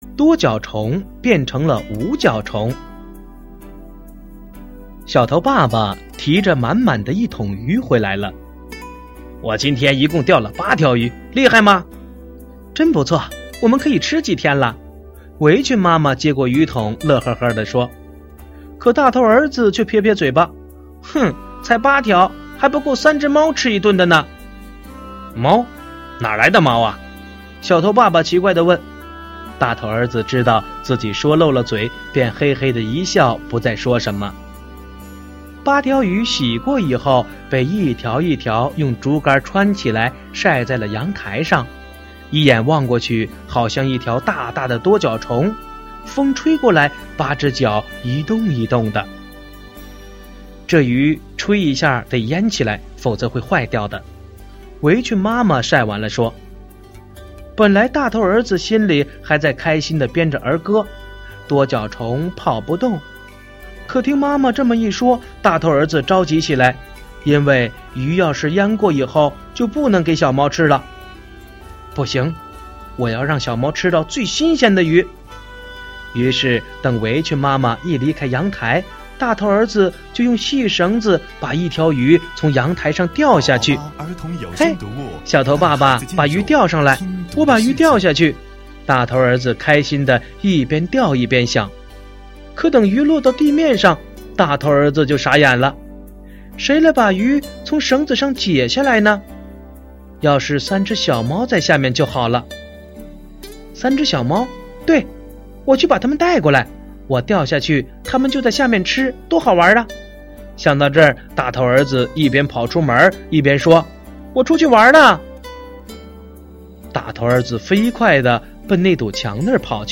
首页>mp3 > 儿童故事 > 06多脚虫变成无脚虫（大头儿子和三只流浪猫）